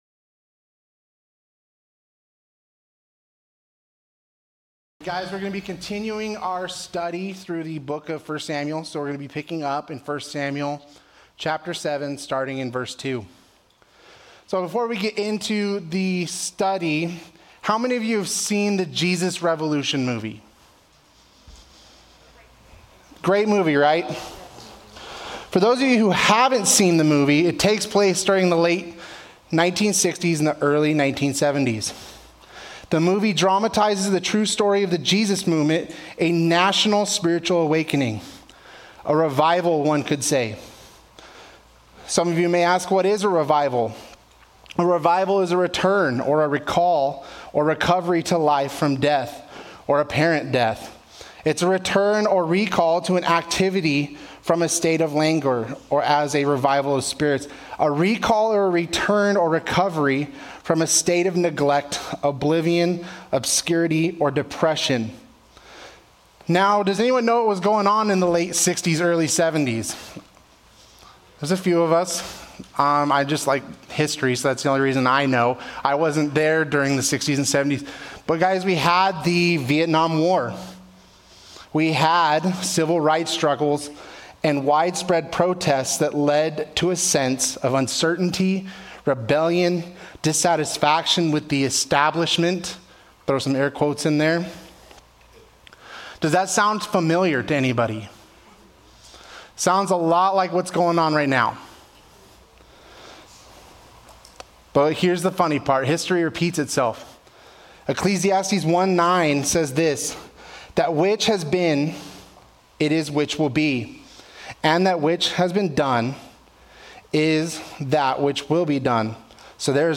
Calvary Chapel Saint George - Sermon Archive
Related Services: Wednesday Nights